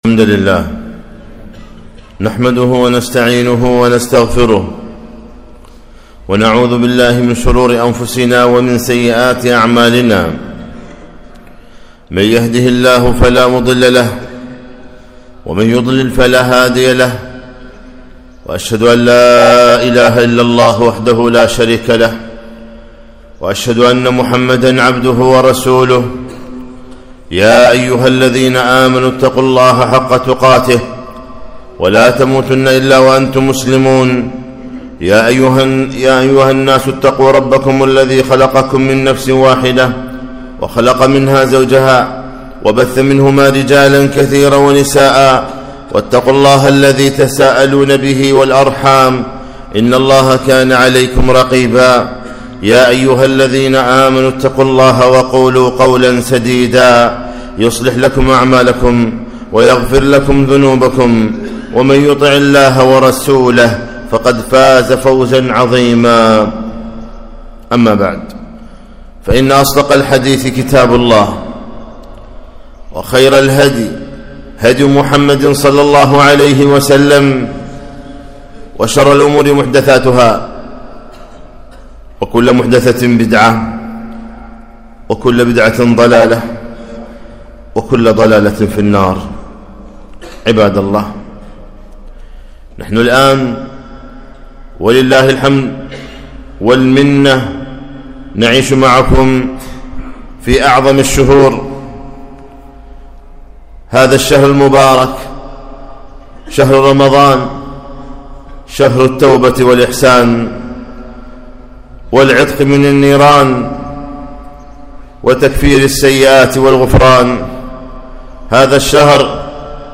خطبة - (توبوا إلى الله)